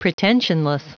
Prononciation du mot pretensionless en anglais (fichier audio)
Prononciation du mot : pretensionless